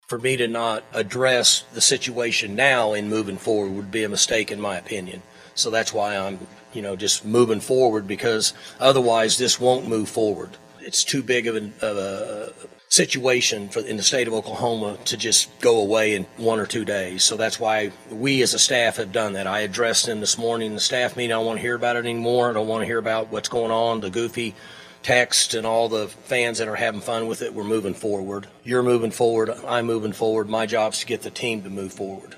Oklahoma State head football coach Mike Gundy had his weekly press conference on Monday ahead of the Cowboy’s trip to UCF.